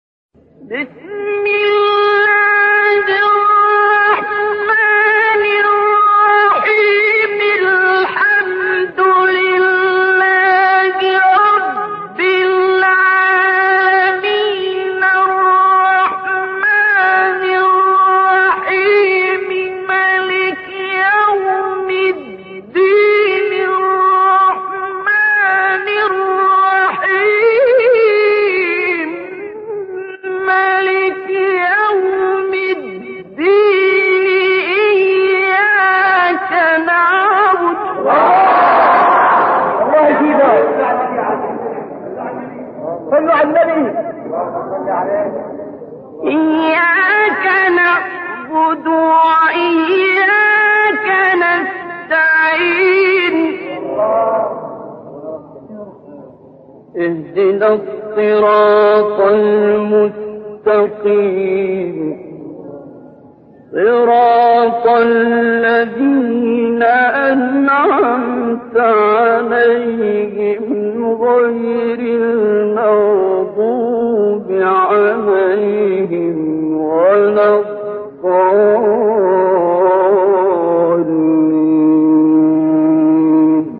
تلاوت تاریخی و آرامش بخش استاد عبدالباسط_عبدالصمد (رحمة الله علیه)
سوره حمد با صدای زیبای عبدالباسط